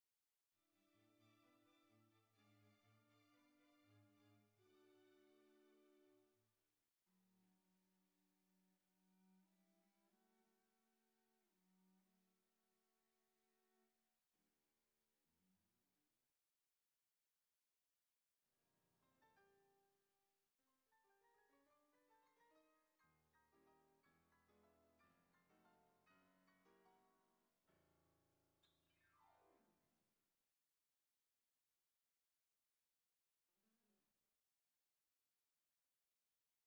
of orchestra music recorded at -60dB.
You can HEAR the stairstepped quantization noise.